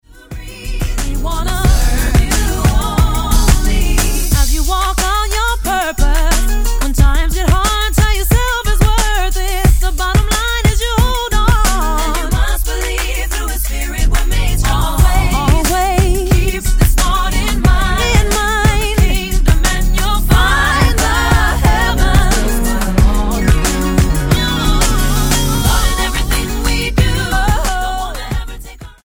STYLE: Gospel
A delicious blend of R&B soul and latin rhythms